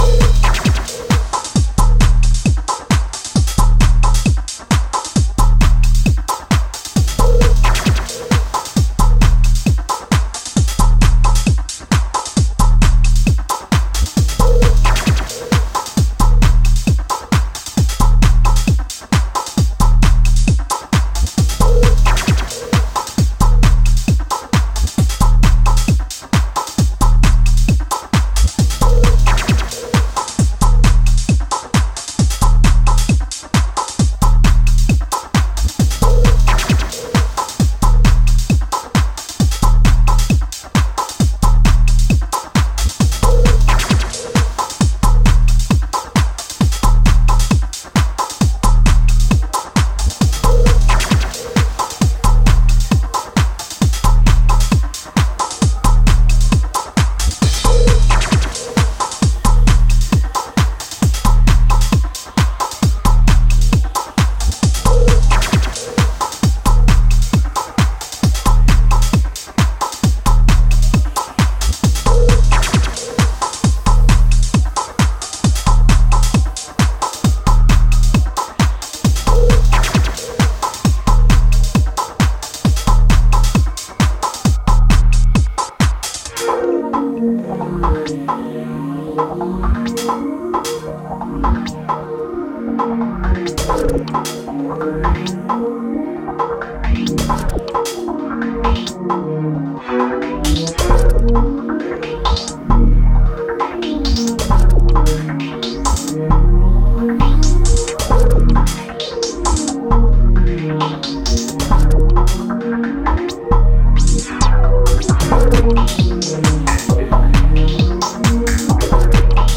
early South London Tech House sound